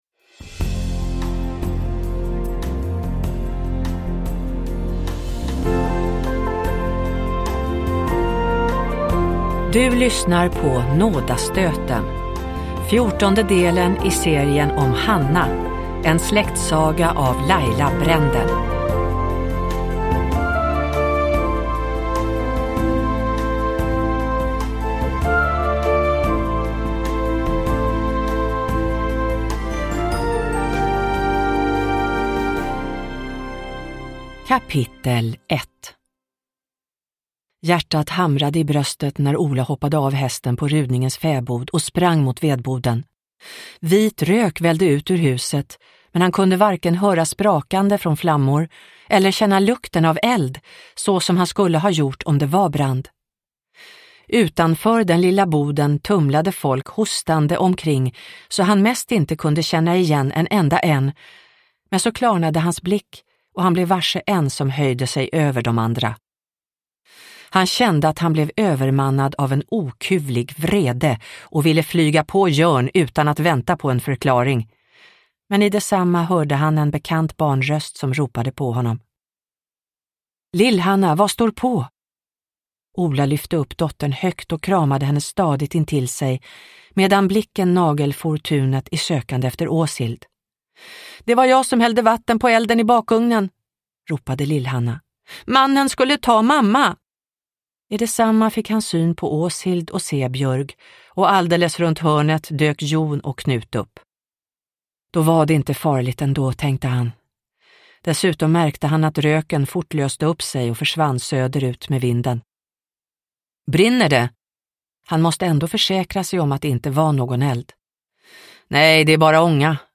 Nådastöten – Ljudbok – Laddas ner